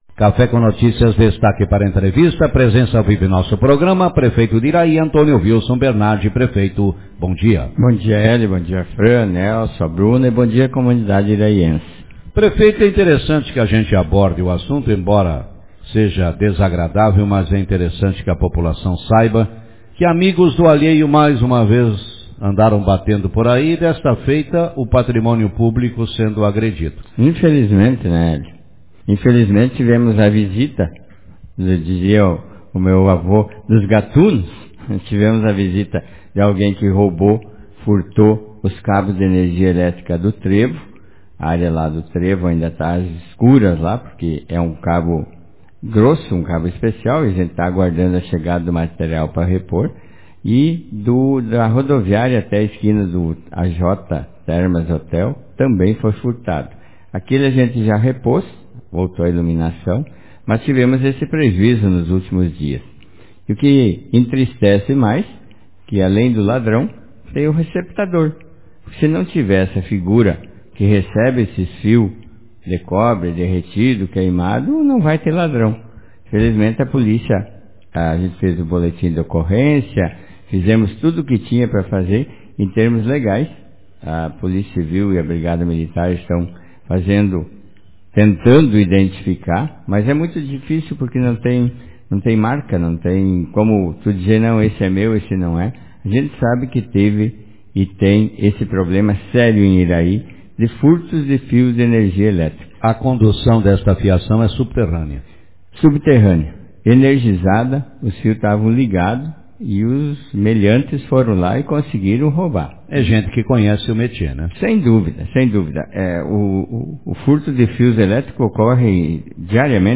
Prefeito de Iraí destaca ações do Executivo durante a semana Autor: Rádio Marabá 19/01/2023 Manchete O prefeito de Iraí, Antonio Vilson Bernardi, participou nesta manhã do programa Café com Notícias e falou sobre as atividades do Executivo durante esta semana. Entre os assuntos abordados estão o furto de fios pertencentes a iluminação pública e as obras de recapeamento asfáltico que devem recomeçar na próxima semana.